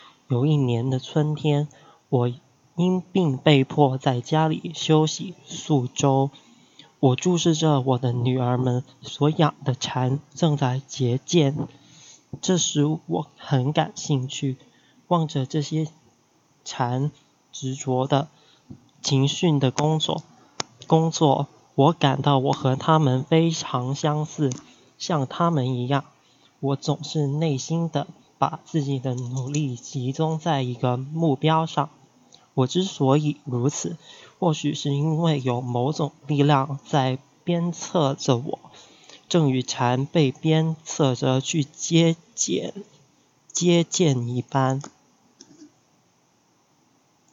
Task 3 Passage Reading
Gender : Male
First Language : Cantonese
Third Language : Mandarin